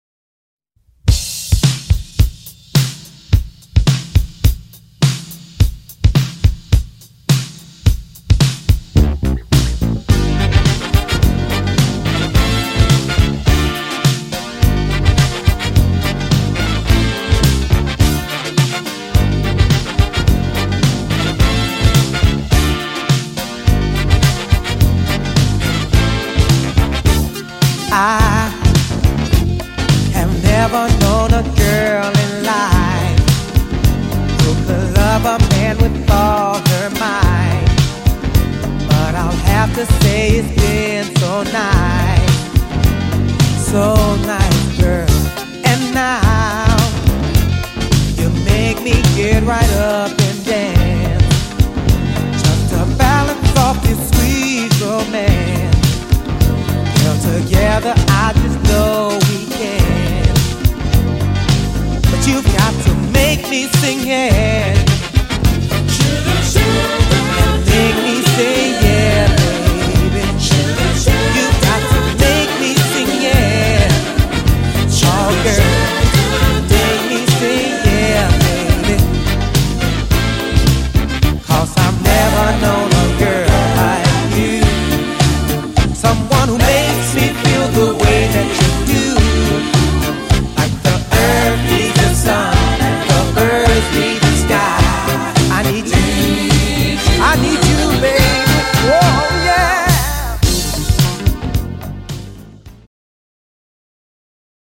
・両サイドともに今回の7"シングル用のリマスター音源を使用
・両面45回転仕様で音圧をしっかりとキープ
ジャンル(スタイル) SOUL / DISCO